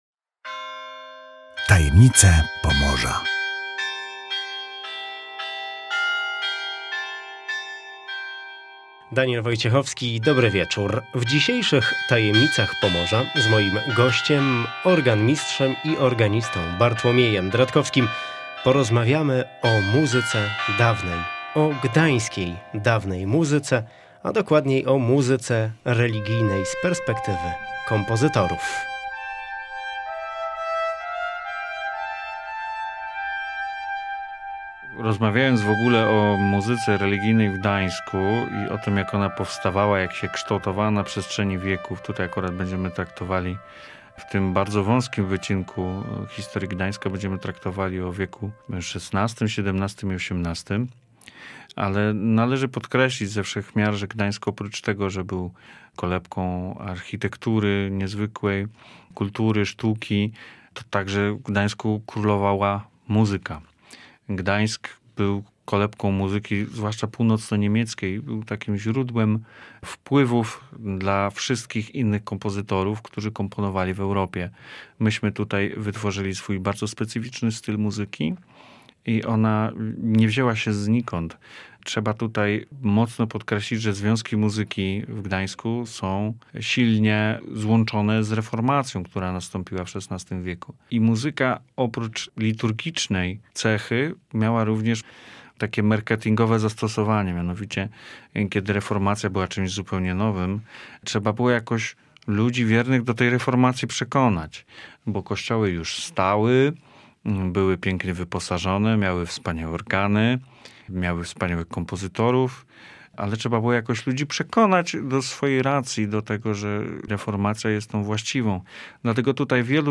Co łączy Gdańsk z Georgiem Philippem Telemannem, słynnym niemieckim kompozytorem epoki baroku? Kto był uczniem Bacha, a kto może śmiało konkurować twórczo z Charpentierem? Wszystkie te tajemnice rozwiewa rozmówca